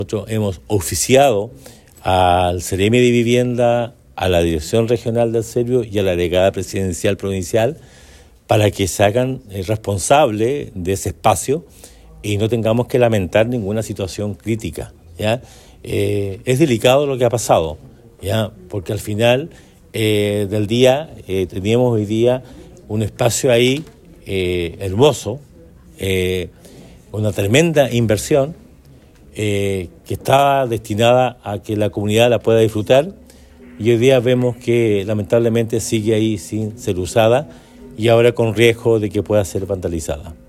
Para tratar de mitigar esta situación, el alcalde informó que también se han enviado oficios a la Delegación Presidencial, a la Seremi de Vivienda y a Serviu Los Lagos, solicitando de manera formal que tomen las medidas de seguridad necesarias para proteger el lugar mientras se gestiona la entrega oficial. Esta solicitud incluye la instalación de medidas de resguardo temporales y una vigilancia que permita mantener el estado de las obras en condiciones óptimas hasta que se concrete la entrega final.